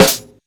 Snares
livingz_snr (2).wav